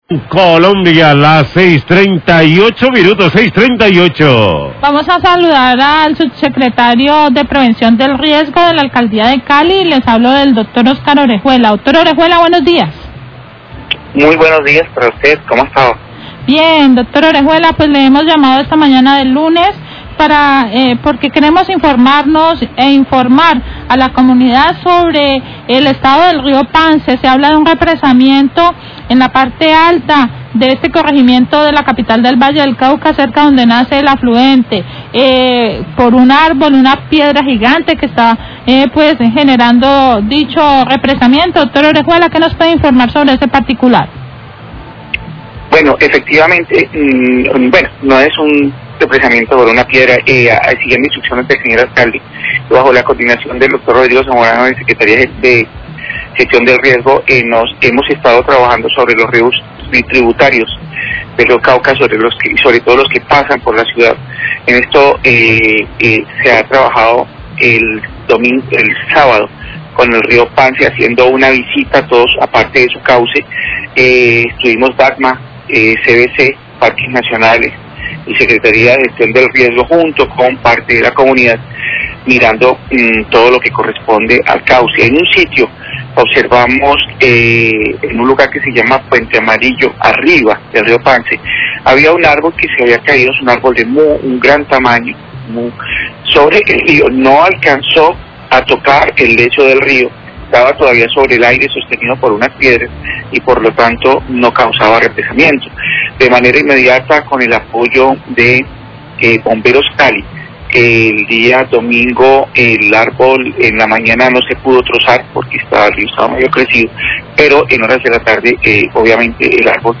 SUBSECRETARIO DE GESTIÓN DE RIESGO SOBRE CAÍDA ÁRBOL EN RÍO PANCE, RADIO CALIDAD, 6.38am